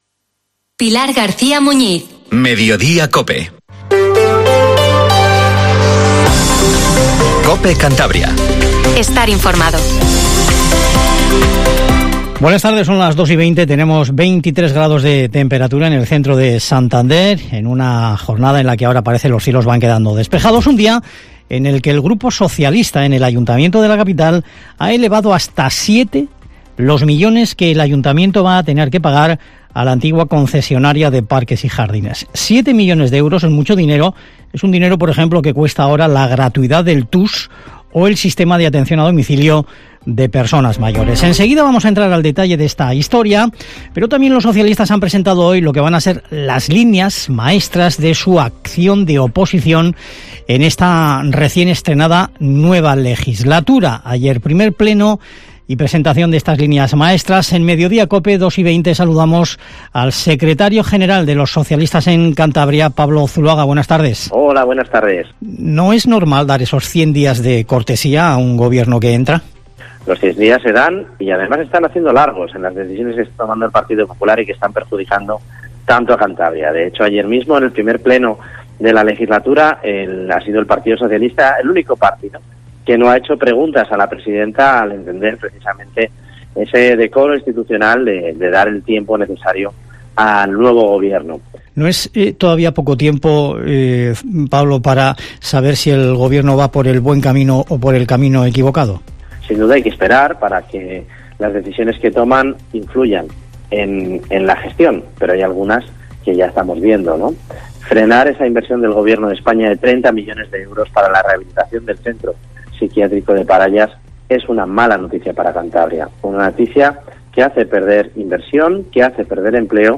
Informativo MEDIODIA en COPE CANTABRIA 14:20